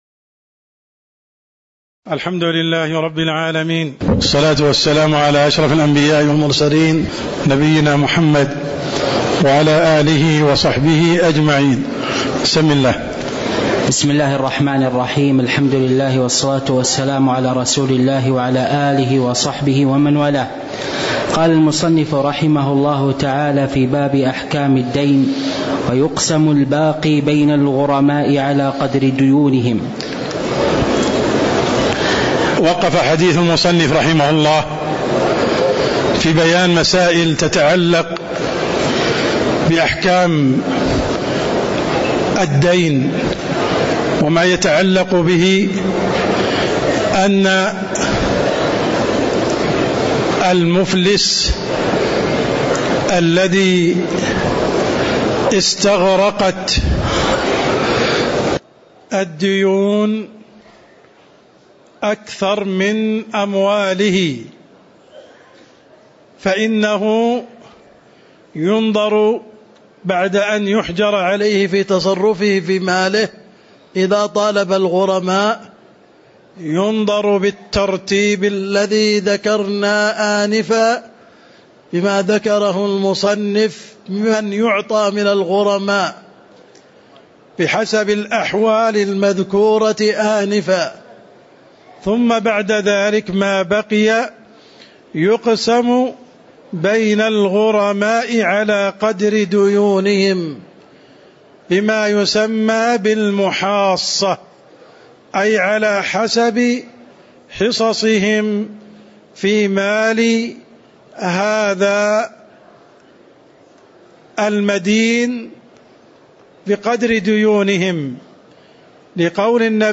تاريخ النشر ٢٧ جمادى الآخرة ١٤٤١ هـ المكان: المسجد النبوي الشيخ: عبدالرحمن السند عبدالرحمن السند باب أحكام الدين (08)